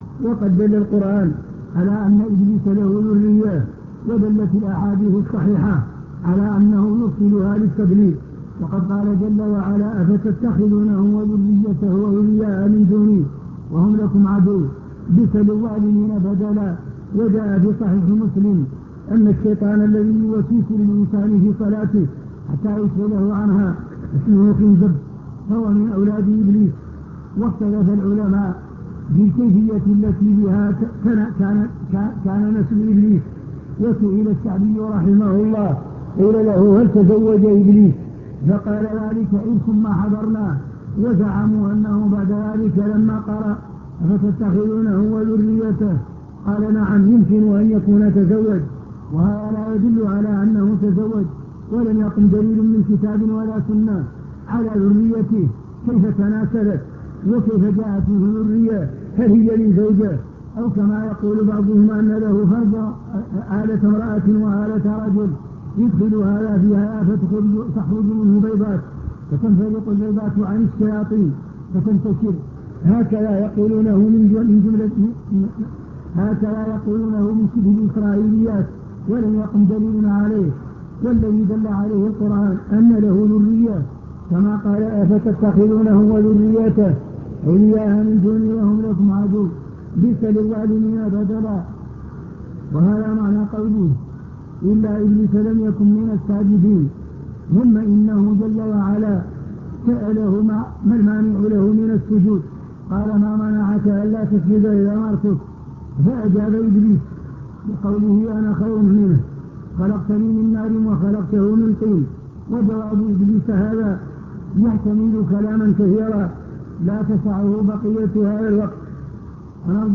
المكتبة الصوتية  تسجيلات - محاضرات ودروس  الرد على ابن حزم